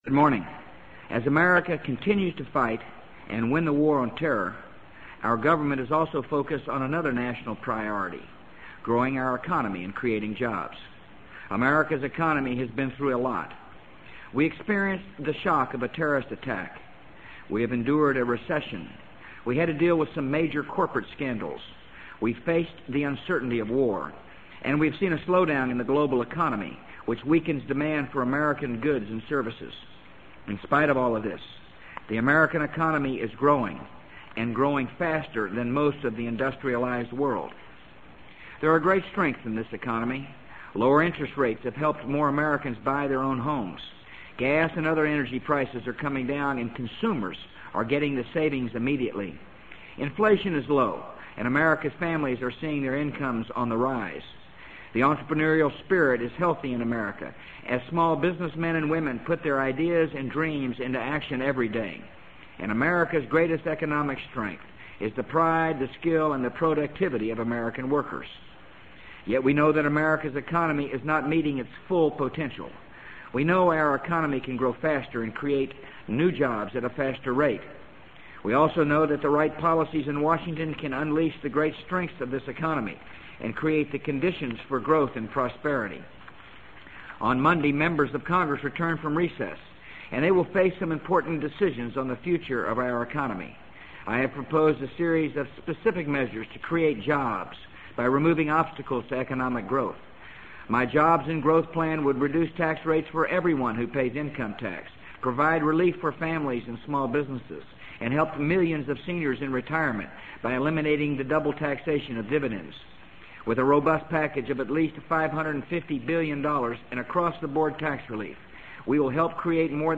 【美国总统George W. Bush电台演讲】2003-04-26 听力文件下载—在线英语听力室